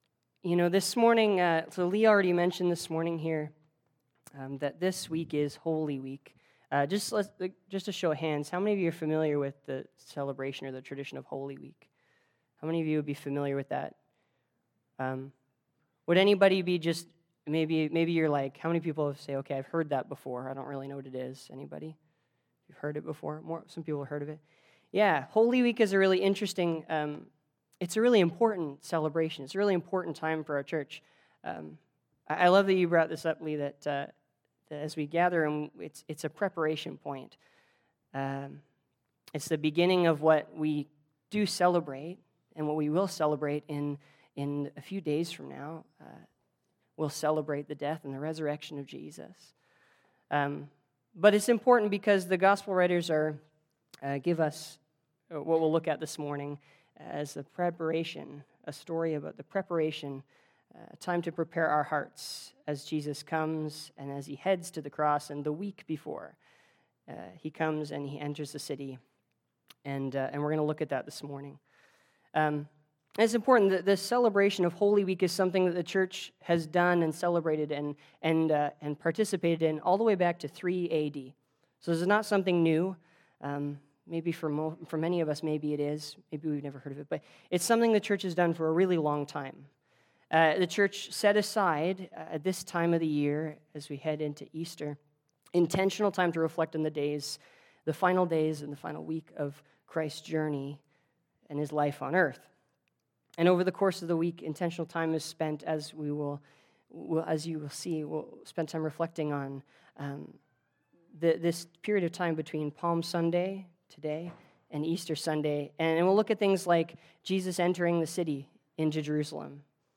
Sermons | Bethany Baptist Church